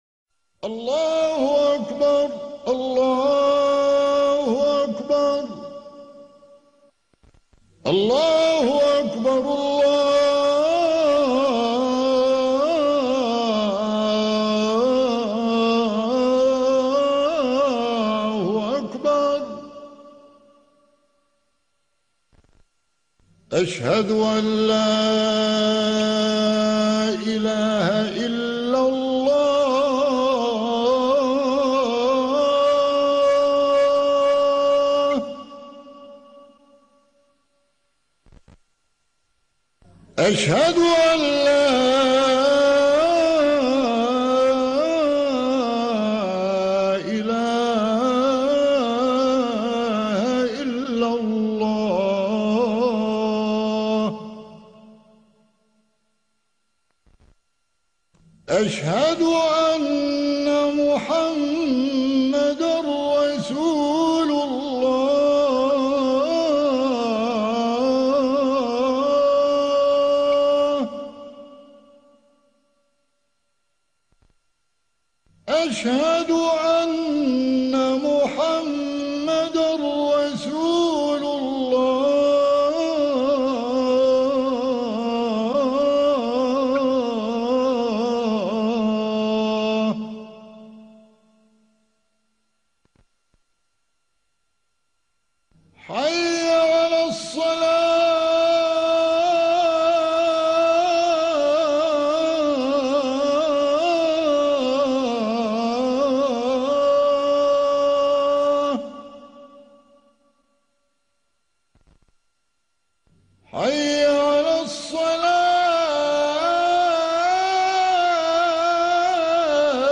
Adhān (the Islamic call to prayer) from differnt countries:
adhan_irak.mp3